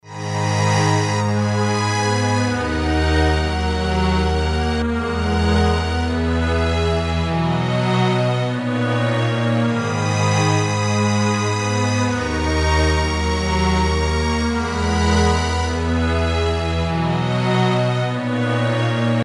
街头传教士
描述：走过一个中世纪小镇的中心，经过小镇的十字架，一个街头传教士正在做他的事情。一位女士正在散发传单。用ZoomH1进行双耳录音。
标签： 双耳 十字架 中世纪 传教士 街道 街道声音
声道立体声